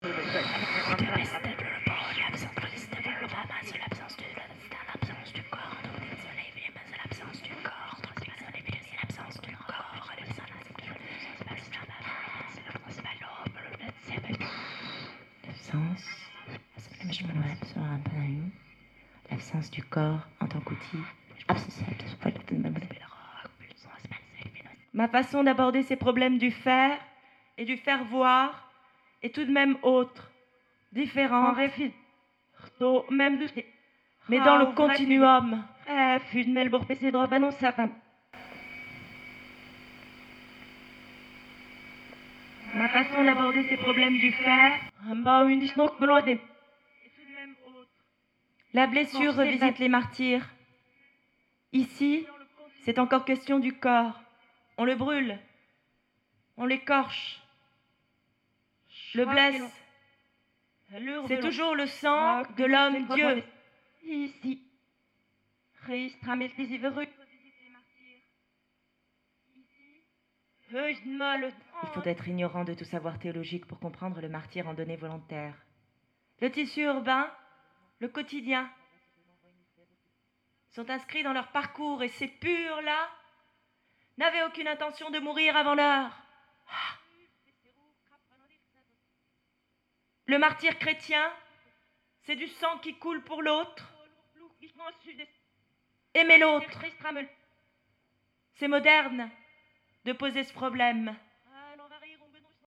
lecture performée 35 minutes / Juin 2011 / Festival Musique Action, Scène nationale de Vandoeuvre, France .
Remettre en jeu les écrits théoriques et pratiques de l'artiste performeuse Gina Pane (affilié au Body art dans les années 70)à travers une performance vocale et électronique, bruitiste et organique; Rémanence d'une voix, d'une pensée dont les propos gardent une puissance lucidité et radicalité à l'époque actuelle. L'aspect formel et stricte des écrits théoriques se meut peu à peu en un poème sonore sensuel et sensible.